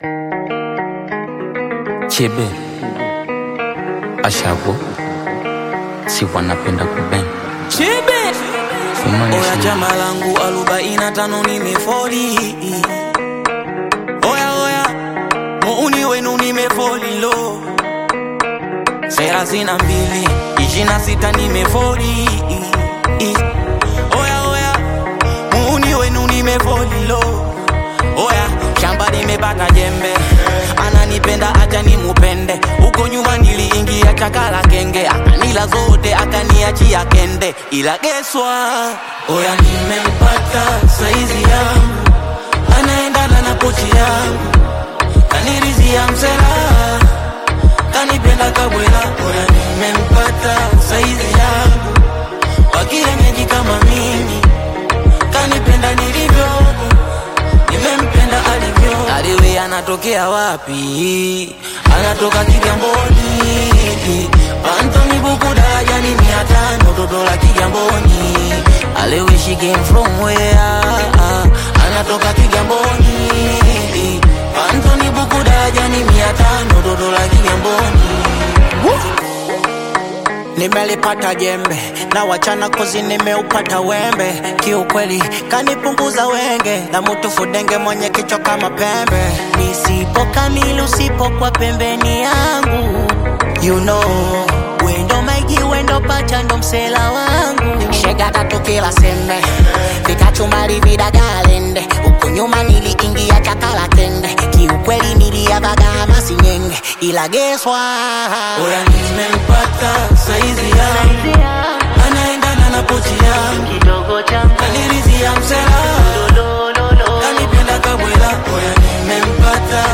Known for their infectious melodies and genre-blending sound
Afrobeats